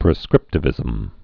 (prĭ-skrĭptə-vĭzəm)